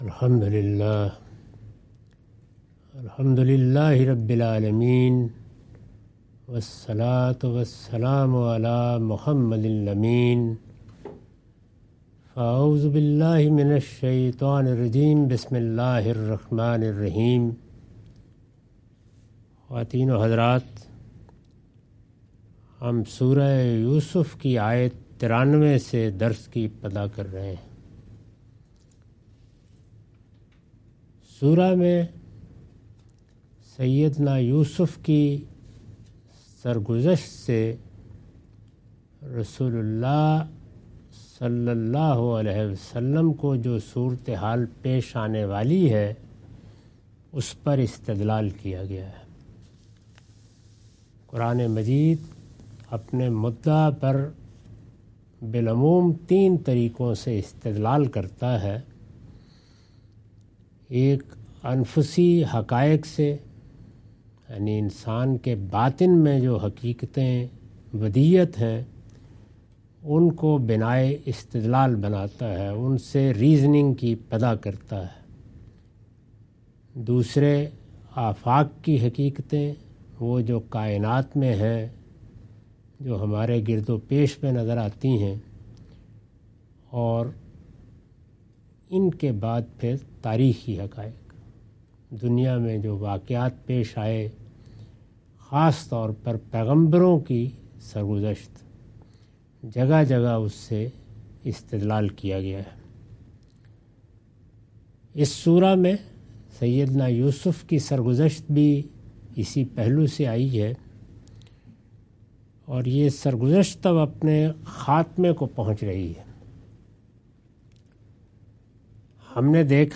Surah Yousuf - A lecture of Tafseer-ul-Quran – Al-Bayan by Javed Ahmad Ghamidi. Commentary and explanation of verses 93-94.